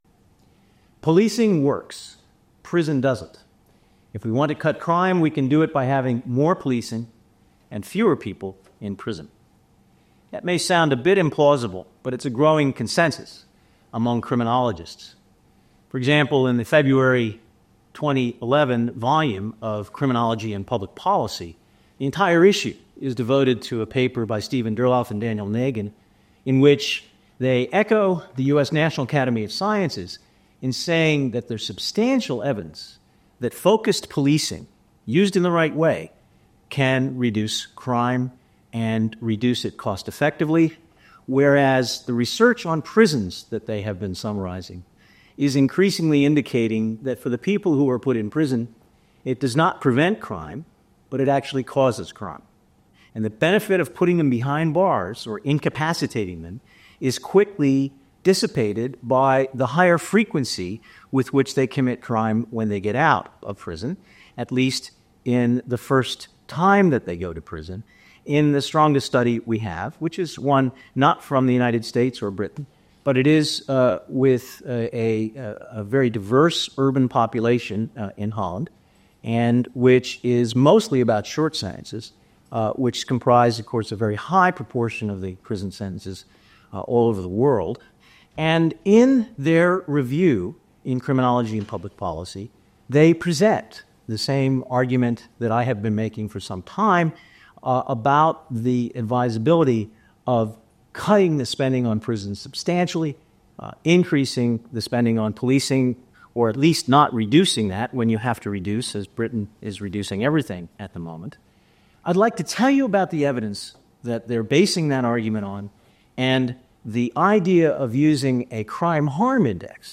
A presentation by Professor Lawrence Sherman, Wolfson Professor of Criminology, Director of the Jerry Lee Centre for Experimental Criminology and Director, Police Executive Programme.